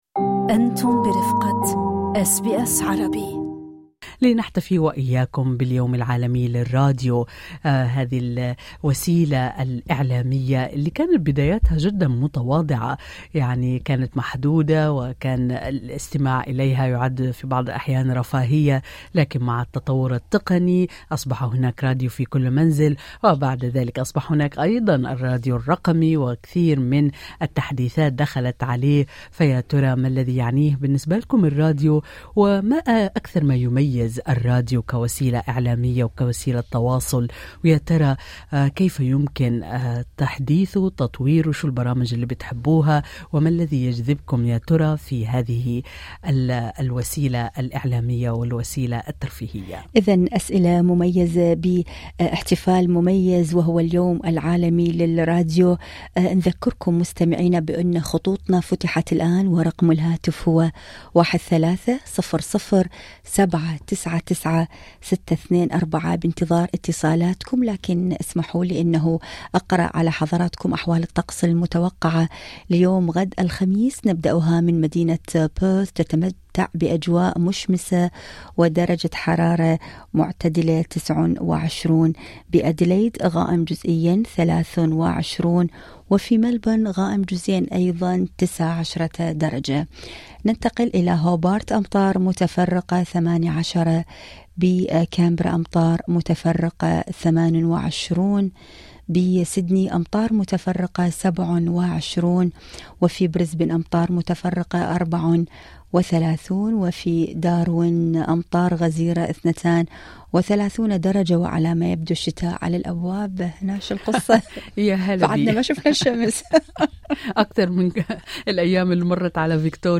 هذا ما عكسه النقاش المباشر ضمن برنامج أستراليا اليوم عبر أثير أس بي أس عربي، حيث شارك المستمعون تجاربهم وذكرياتهم مع وسيلة إعلام رافقتهم في الغربة، وكانت نافذتهم اليومية على الأخبار والمجتمع.
بمناسبة اليوم العالمي للراديو في 13 فبراير/شباط، فتح البرنامج خطوطه لسؤال بسيط لكنه عميق: